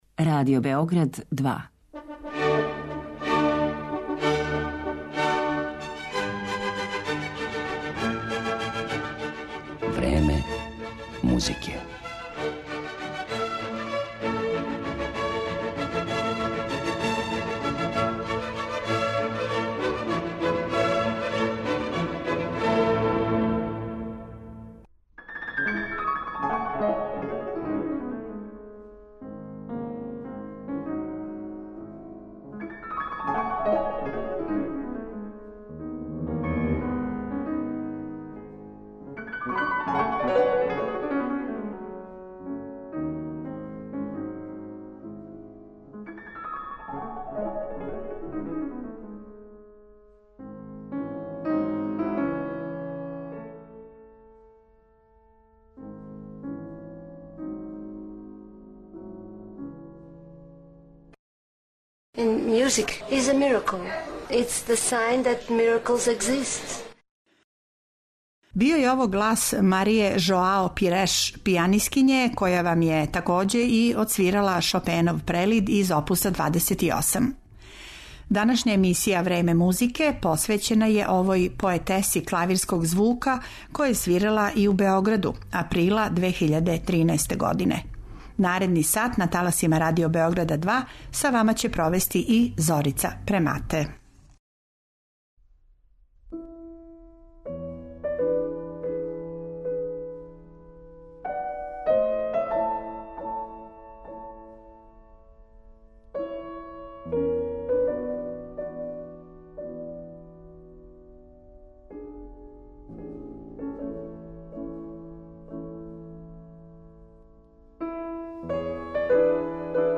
Осим интервјуа у коме износи своју извођачку поетику, као и детаље из каријере и рада на промоцији музике, моћи ћете да чујете и њене снимке на којима свира дела Моцарта и Шопена.